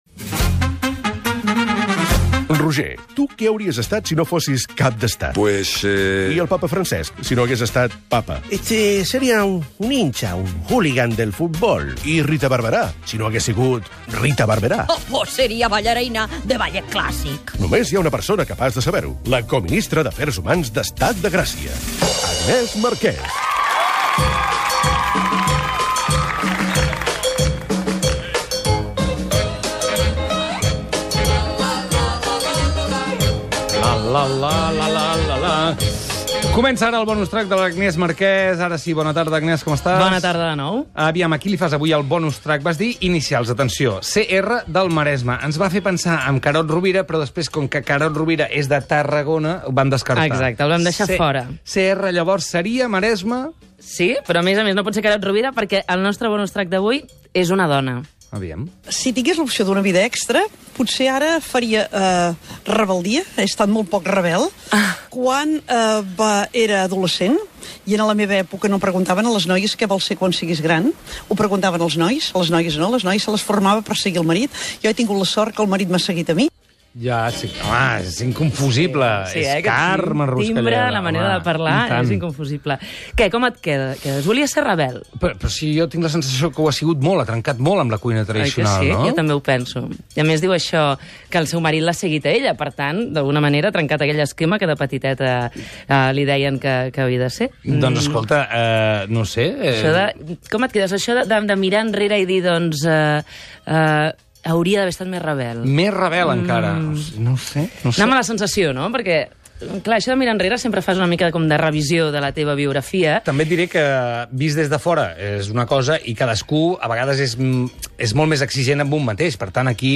Careta de la secció i espai dedicat a la cuinera Carme Ruscalleda i el seu restaurant Sant Pau de Sant Pol de Mar, els seus gustos, records personals i somnis Gènere radiofònic Entreteniment